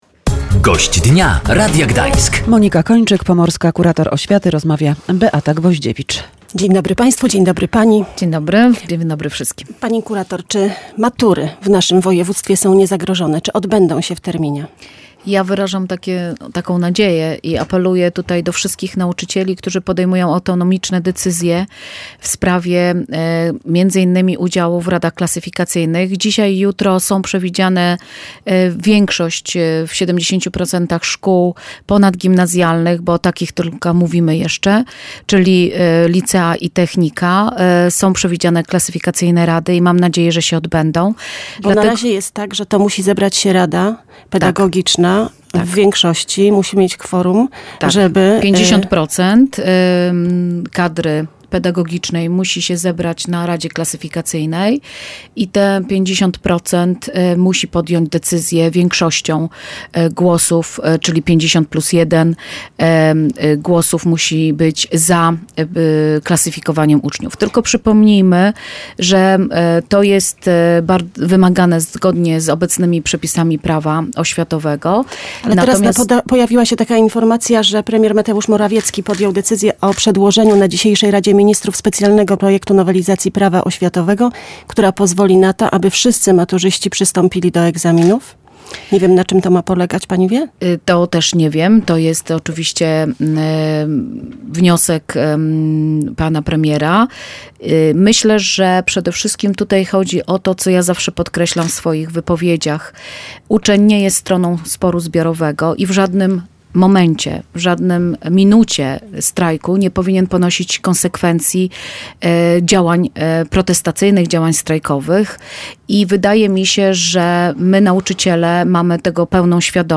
– Matura jest bardzo ważnym egzaminem. Egzaminem dojrzałości nas wszystkich – podkreślała Monika Kończyk, pomorska kurator oświaty, która była Gościem Dnia Radia Gdańsk.